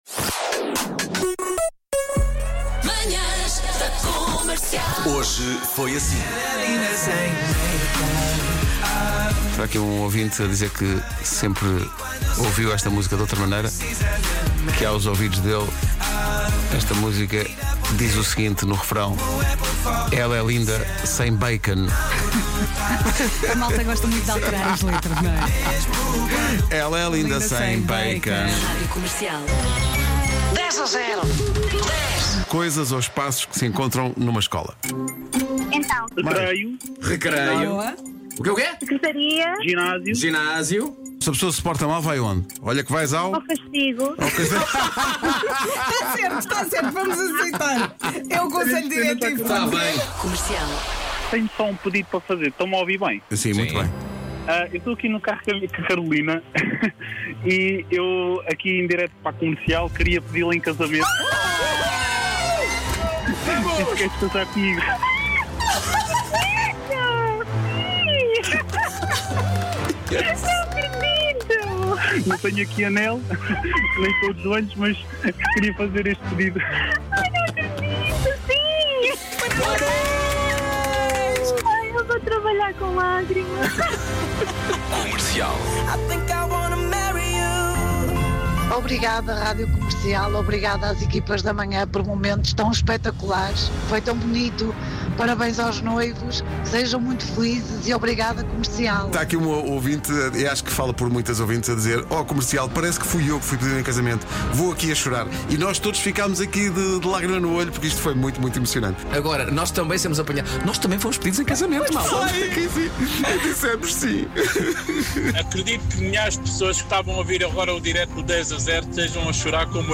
Pedido de casamento em direto no Dejajero!!